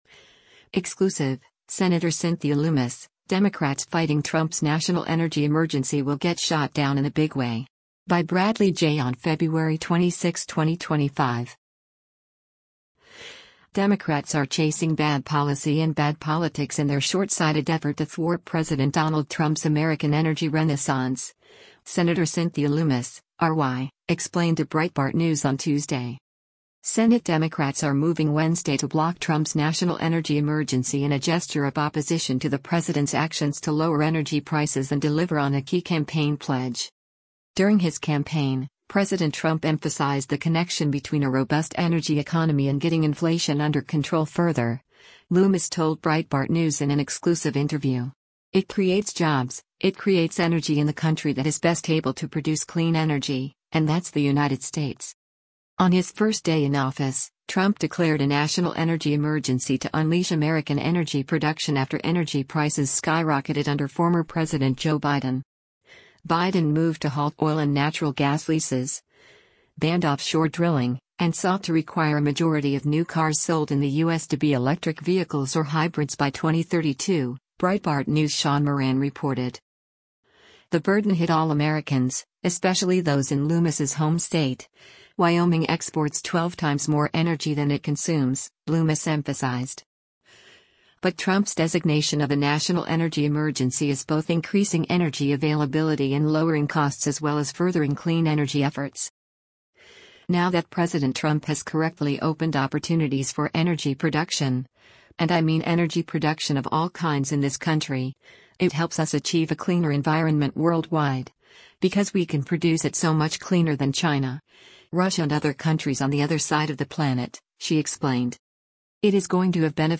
“During his campaign, President Trump emphasized the connection between a robust energy economy and getting inflation under control further,” Lummis told Breitbart News in an exclusive interview.